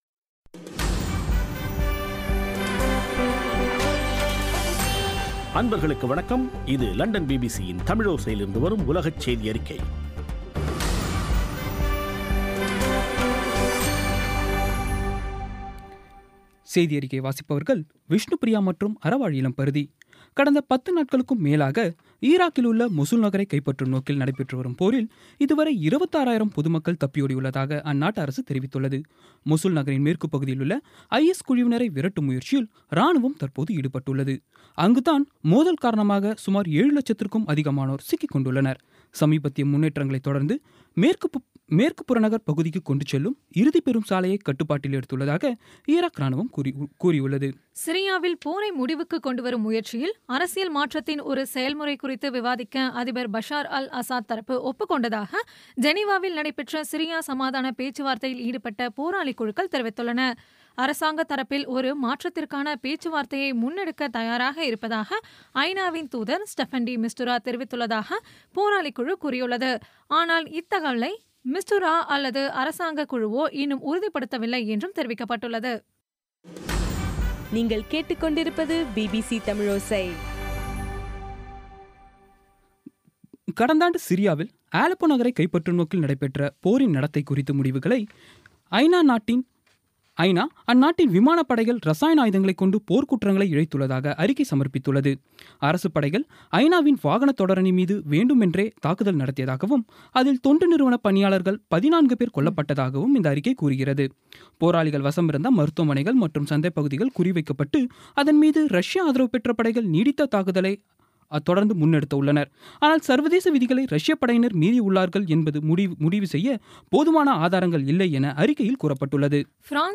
பிபிசி தமிழோசை செய்தியறிக்கை (01/03/17)